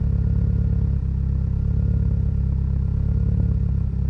rr3-assets/files/.depot/audio/Vehicles/i4_06/i4_06_idle.wav
i4_06_idle.wav